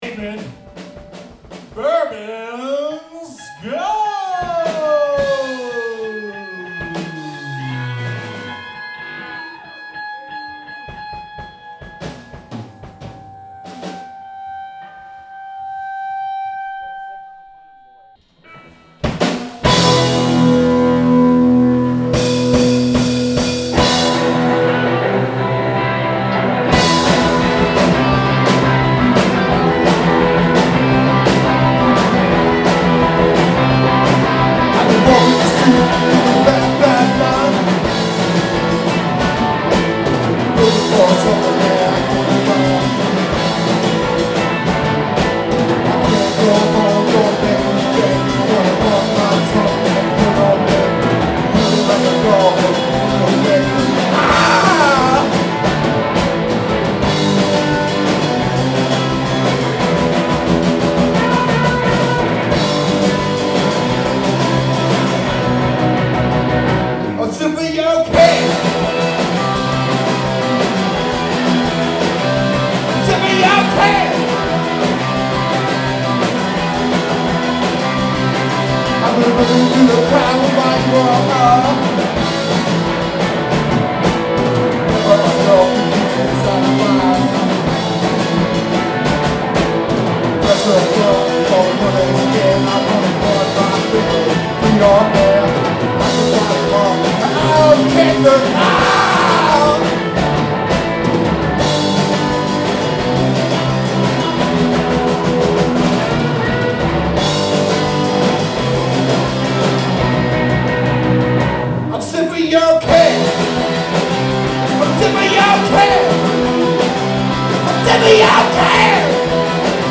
punk band